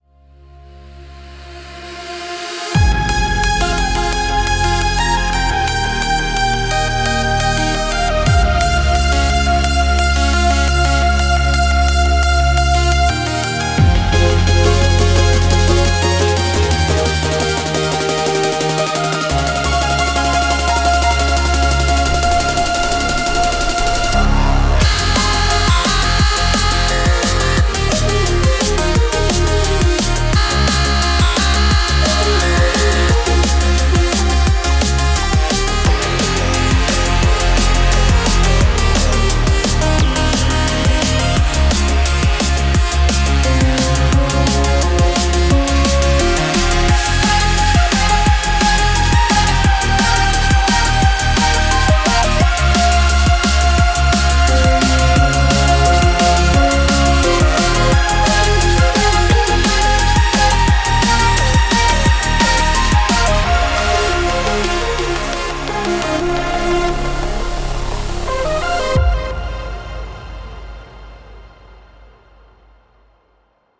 every single sound is created from scratch with Spire.